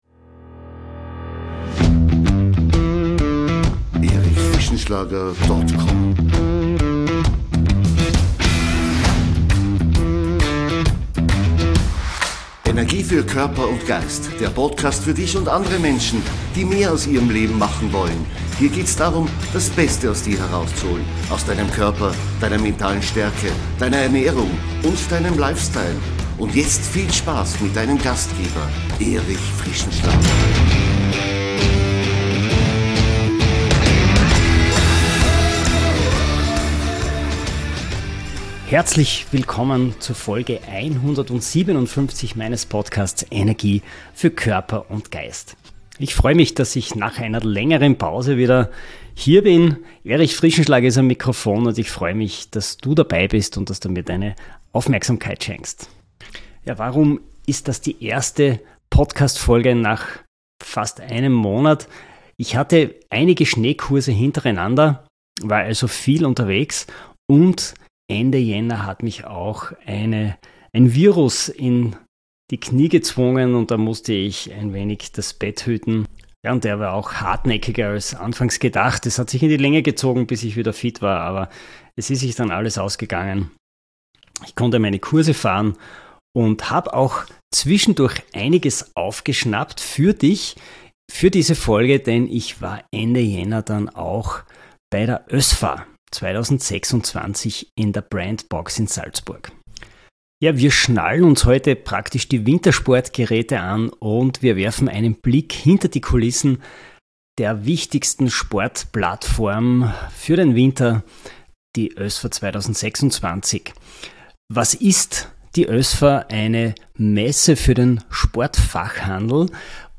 Ich war in den Showrooms der wichtigsten Marken und habe Produkte des nächsten Winters vorab kritisch unter die Lupe genommen. Hier der Überblick über die Kurzinterviews: